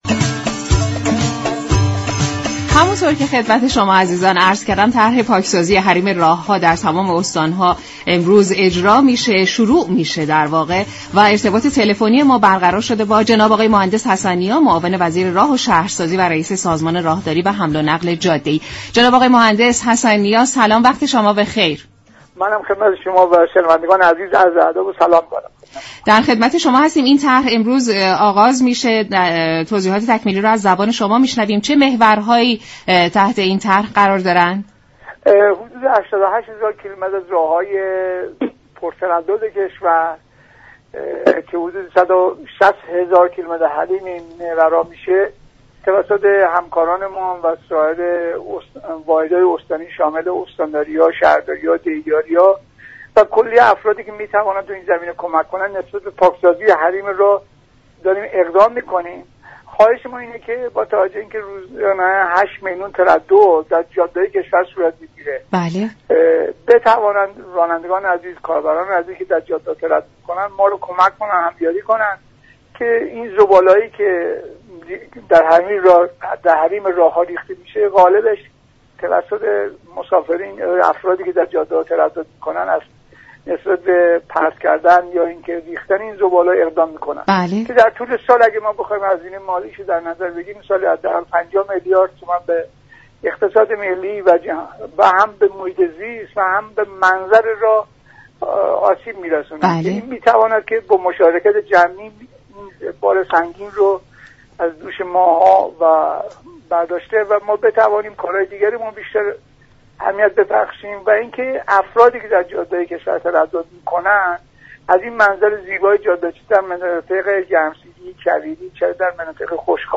معاون وزیر راه و شهرسازی در گفت و گو با رادیو ایران گفت: با توجه به 8 میلیون تردد روزانه در جاده های كشور به رانندگان و هموطنان عزیر توصیه می شود ضمن همكاری با سازمان راهداری از دور ریختن هر گونه زباله به جاده ها خودداری كنند.